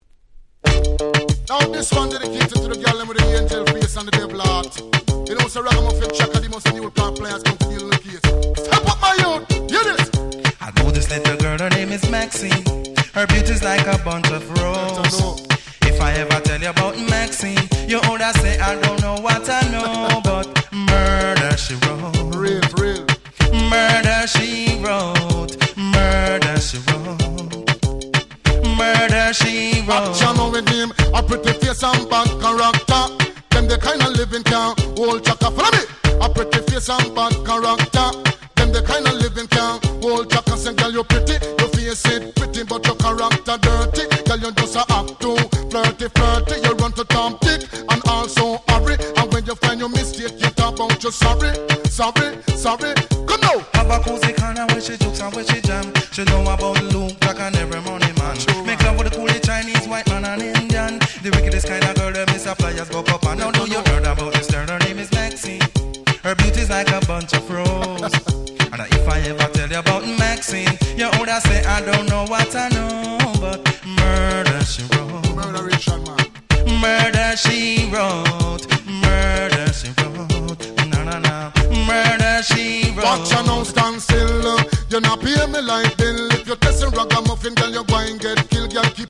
92' Dancehall Reggae Super Classics !!